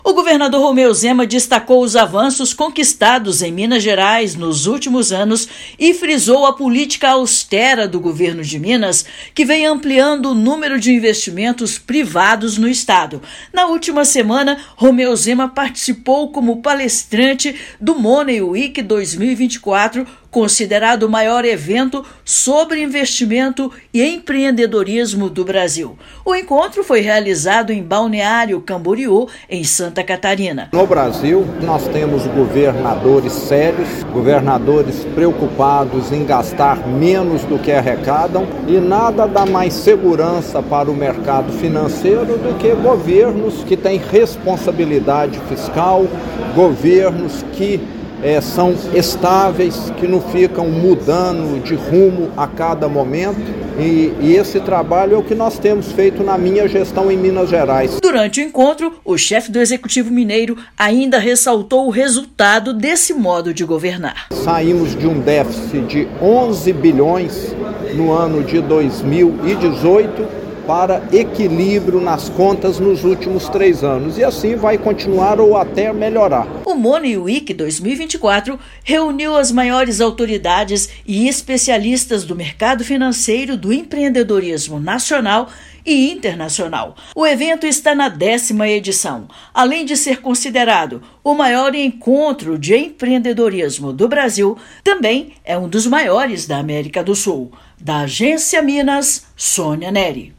[RÁDIO] Governador apresenta resultados da gestão implantada em Minas no maior evento de empreendedorismo do Brasil
Money Week reúne, em Santa Catarina, as maiores autoridades e especialistas do mercado financeiro, nacional e internacional. Ouça matéria de rádio.
PALESTRA_EMPREENDEDORISMO_SANTA_CATARINA.mp3